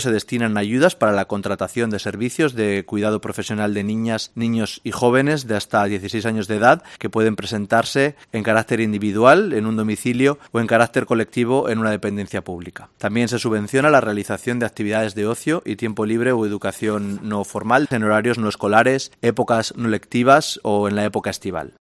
Álex Dorado, consejero de Sostenibilidad y portavoz del Gobierno de La Rioja